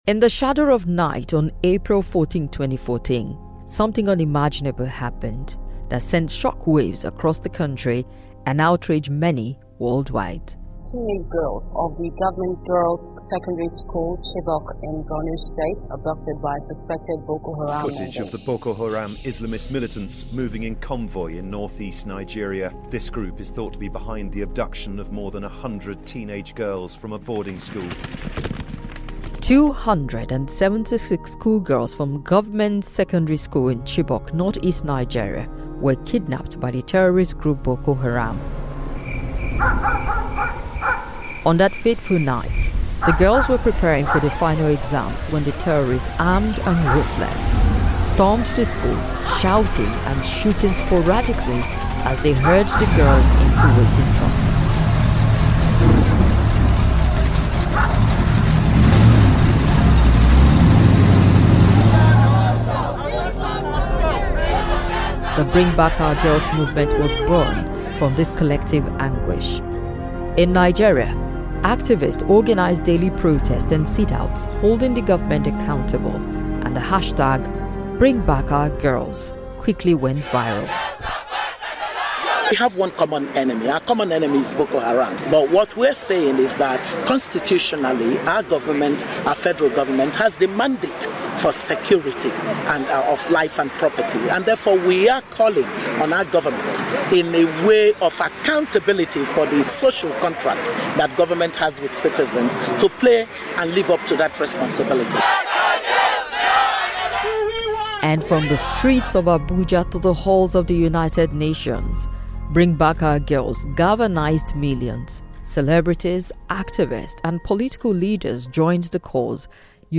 SPECIAL REPORT: Inside Nigeria’s battle against maternal mental health crisis